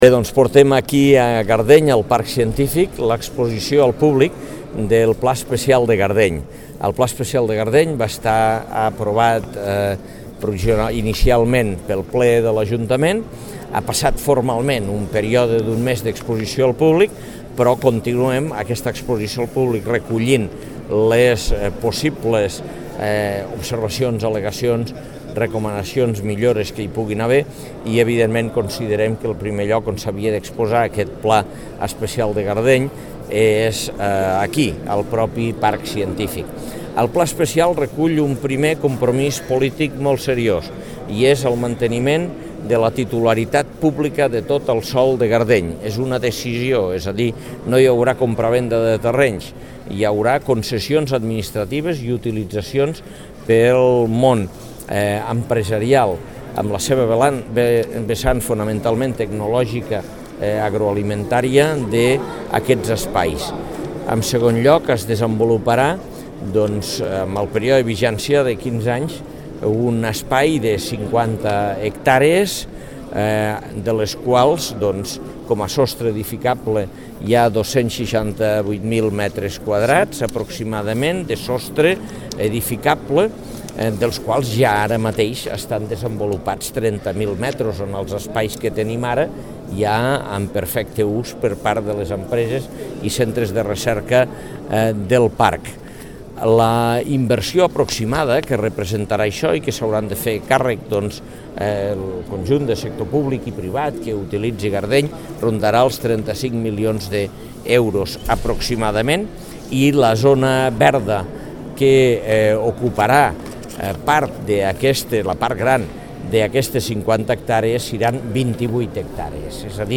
tall-de-veu-de-lalcalde-angel-ros-sobre-lexposicio-divulgativa-sobre-el-pla-especial-urbanistic-del-turo-de-gardeny